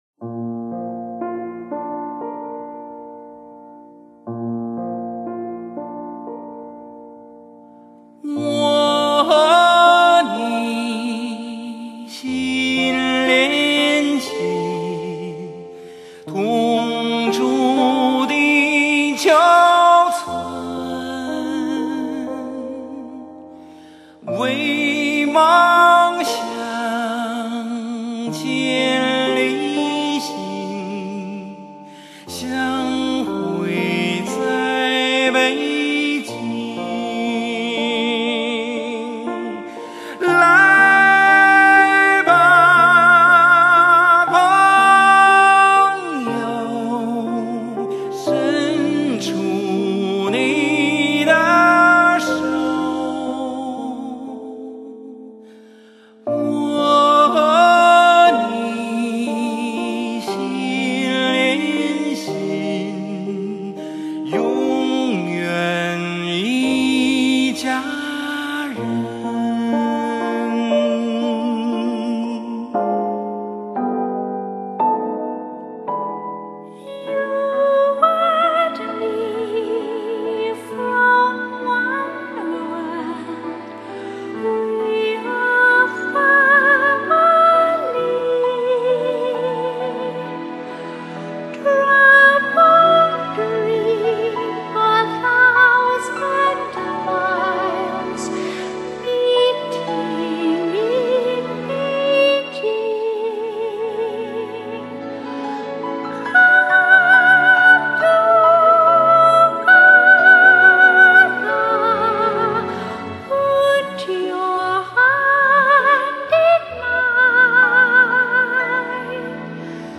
这种抒情是独特的，没有平淡的乏味，没有节奏的喧闹，只有抒情的自然流露，
这是一种极其珍贵的发烧的音乐，一种淡淡而回味无穷的歌曲。